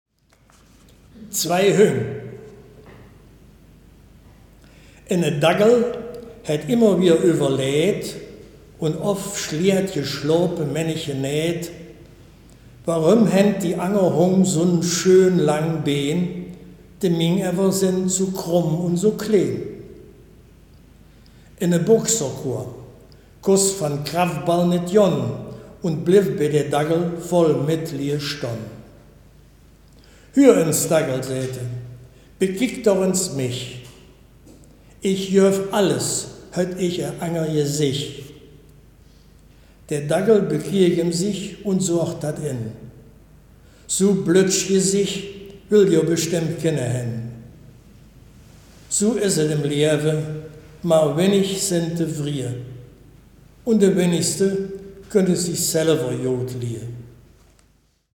Text Mundart
Baaler Riedelland - Erkelenzer Börde
Gedicht